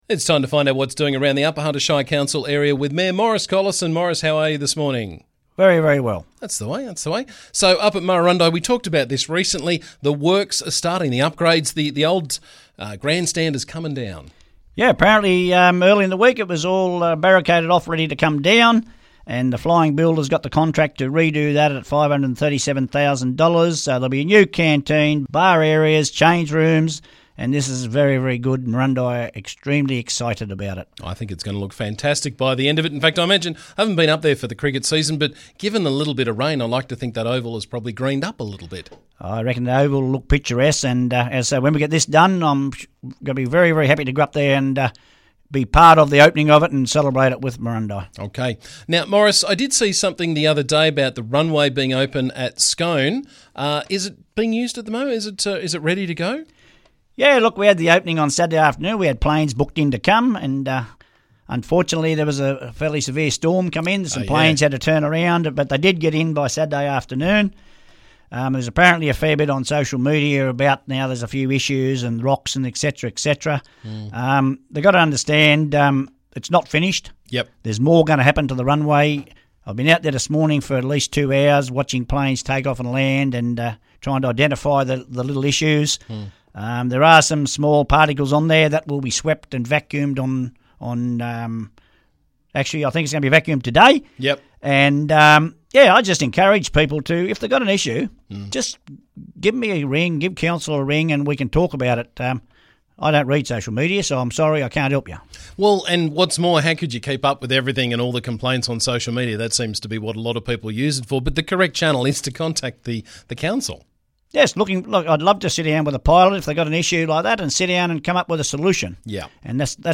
Upper Hunter Shire Council Mayor Maurice Collison caught us up with the latest from around the district.